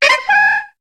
Cri de Pijako dans Pokémon HOME.